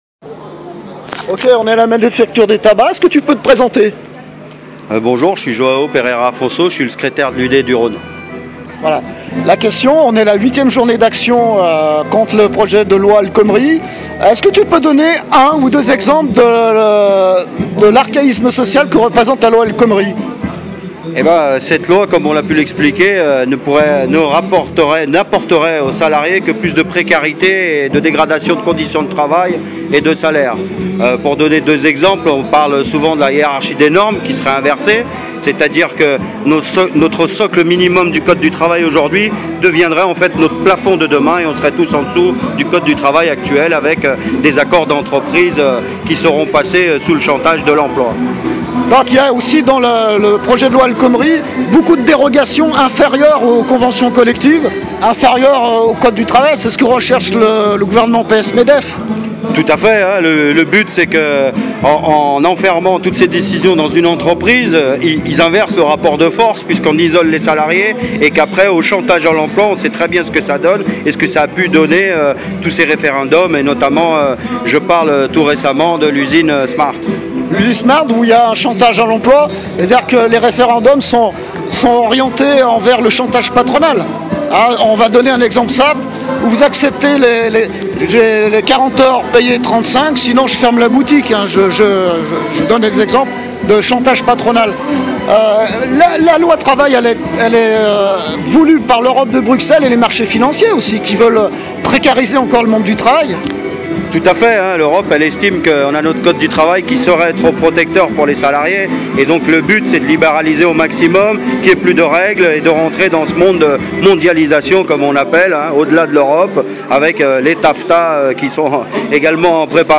AUDIO ENTRETIENT
MANIF LYON